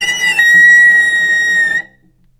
healing-soundscapes/Sound Banks/HSS_OP_Pack/Strings/cello/sul-ponticello/vc_sp-B5-ff.AIF at b3491bb4d8ce6d21e289ff40adc3c6f654cc89a0
vc_sp-B5-ff.AIF